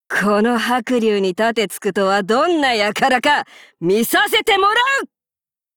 Cv-39905_battlewarcry.mp3